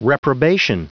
Prononciation audio / Fichier audio de REPROBATION en anglais
Prononciation du mot : reprobation
reprobation.wav